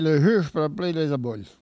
Il crie pour attirer l'essaim d'abeilles
Langue Maraîchin